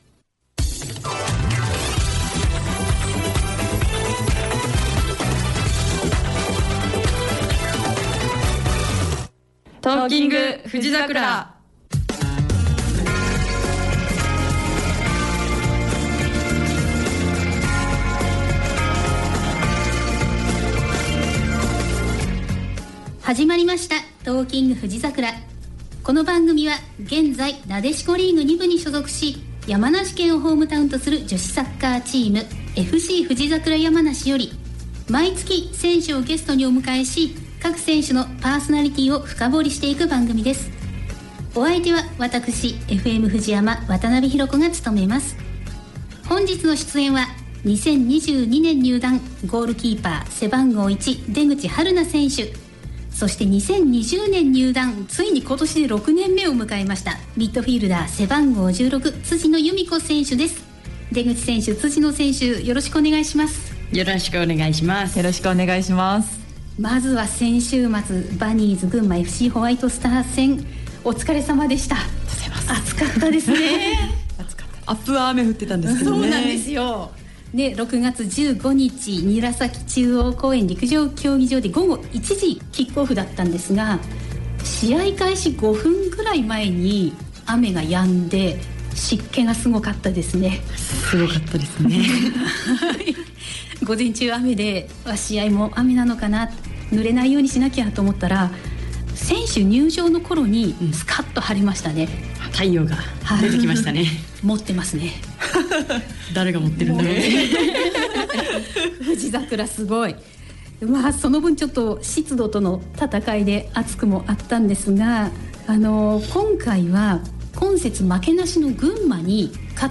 「Talking！ふじざくら」2025年6月19日(木)放送分のアーカイブを公開します。